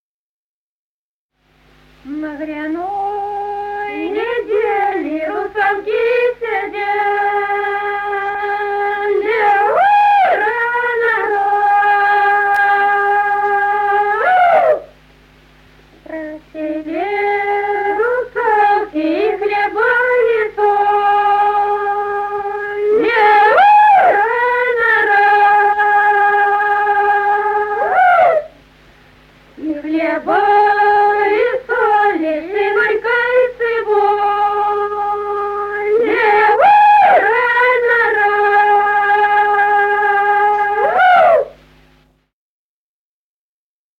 Народные песни Стародубского района «На гряной неделе», гряная.
с. Курковичи.